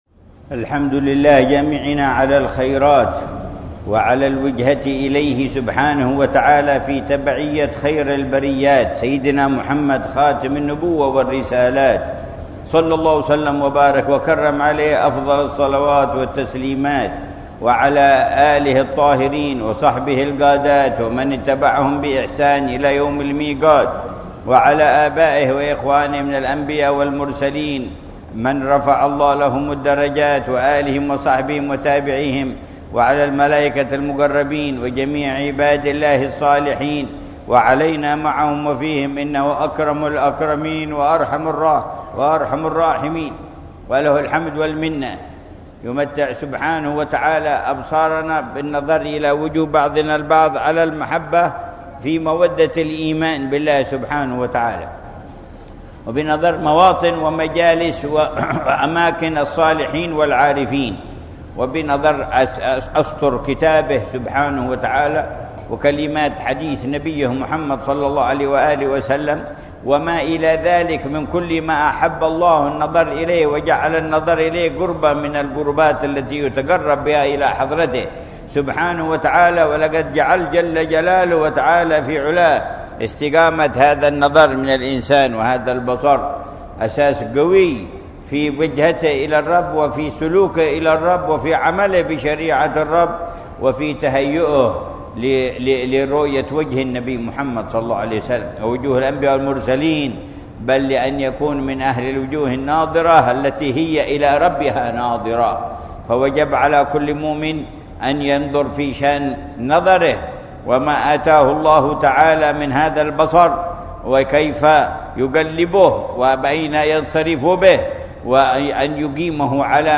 مذاكرة العلامة الحبيب عمر بن محمد بن حفيظ في جامع الإيمان بعيديد، تريم، حضرموت، ليلة الخميس 4 ربيع الثاني 1445هـ بعنوان: استقامة النظر أساس في الوجهة والعمل بالشريعة ونتائجه